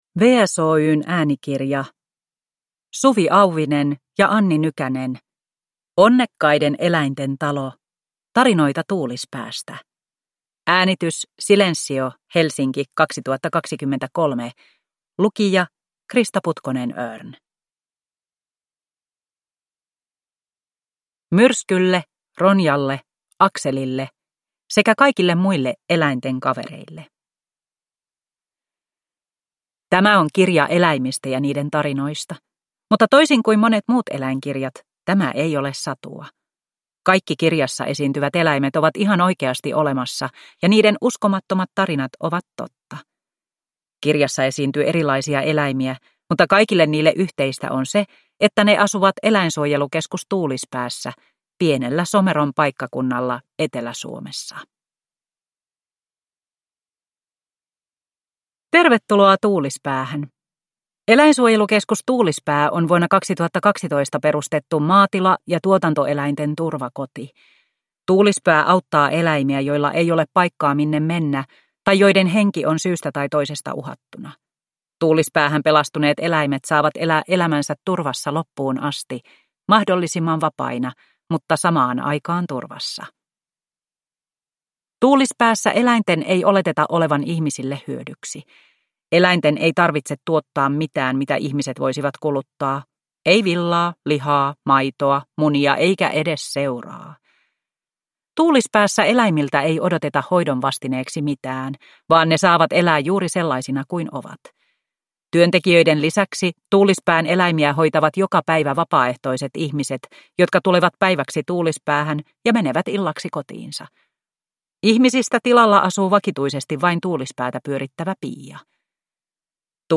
Onnekkaiden eläinten talo – Ljudbok – Laddas ner